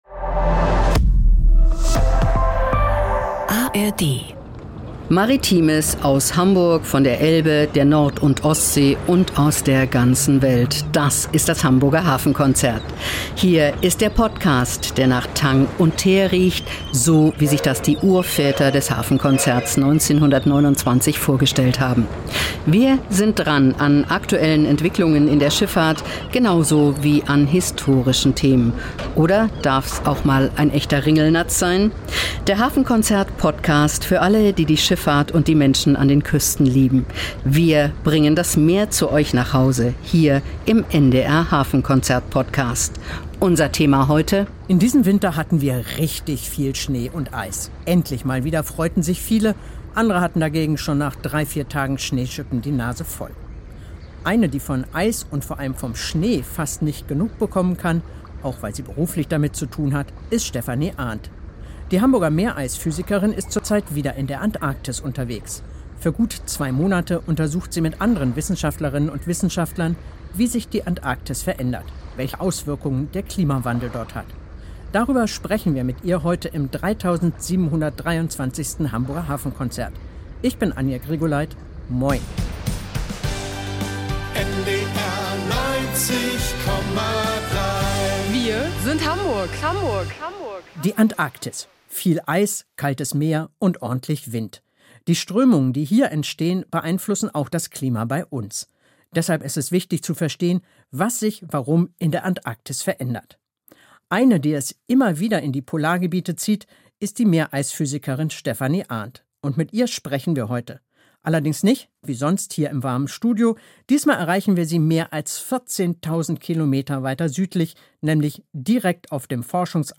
Mit ihr sprechen wir direkt auf dem Forschungs- Eisbrecher des Alfred-Wegener-Instituts.